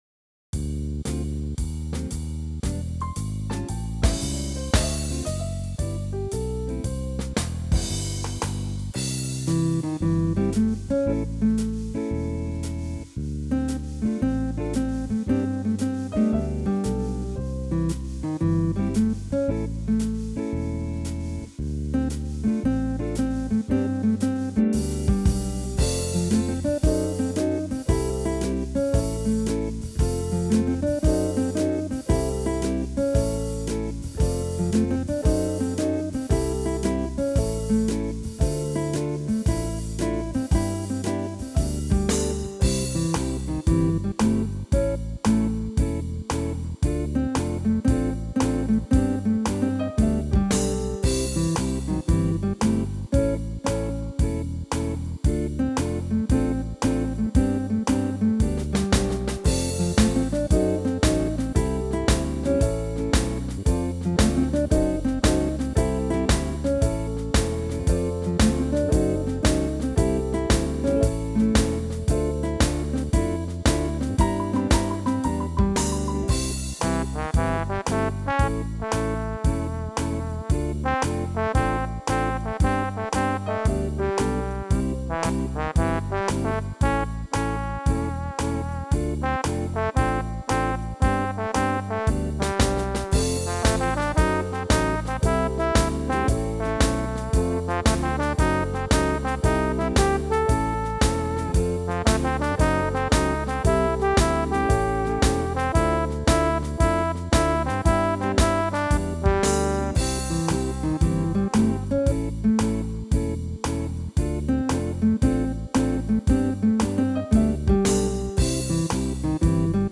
(sorta jazzy)